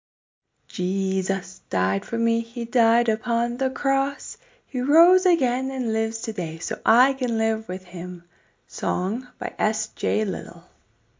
Christian Song
Tune: Row Row Row Your Boat